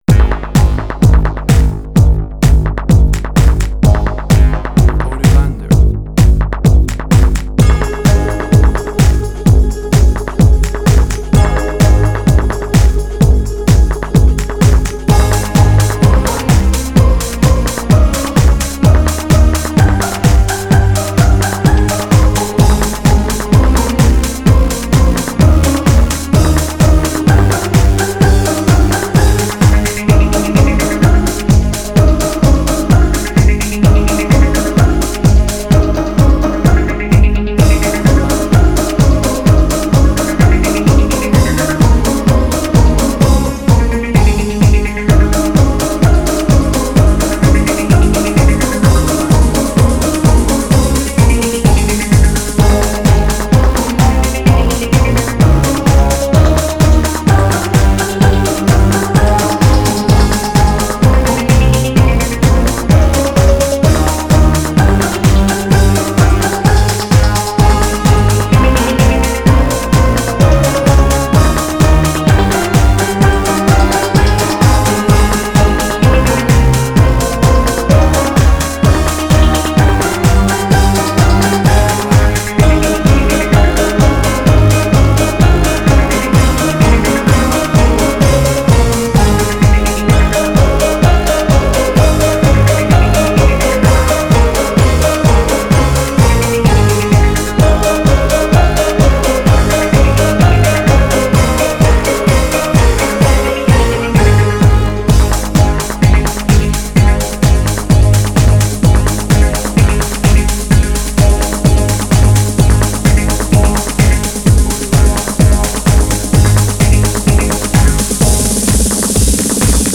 Jewish Techno Trance.
Tempo (BPM): 128